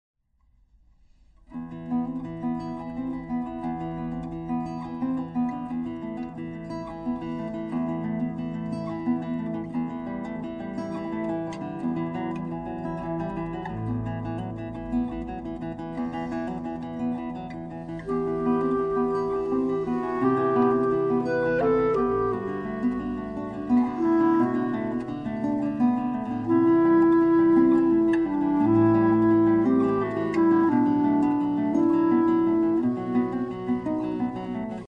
Clarinet
Guitar